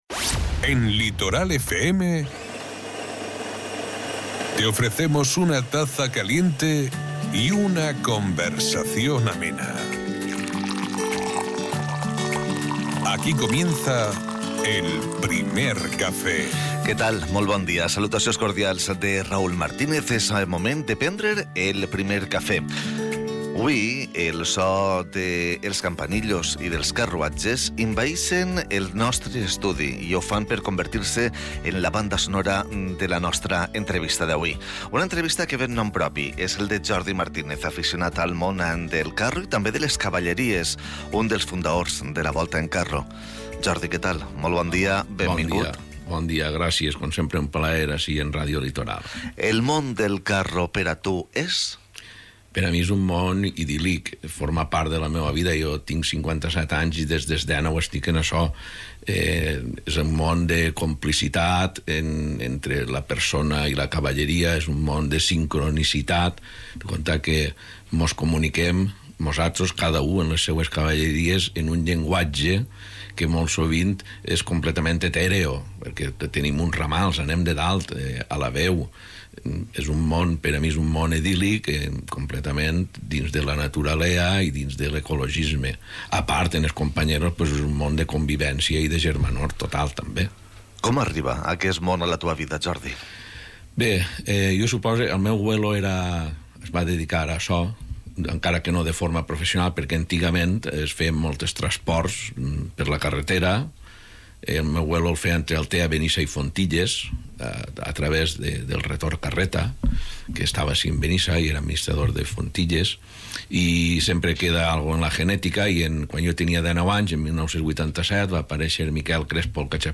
Su presencia ha llenado el estudio de calidez, y el tintinear de los cascabeles y el rodar de los carros tradicionales han puesto banda sonora a una entrevista cargada de emoción y autenticidad.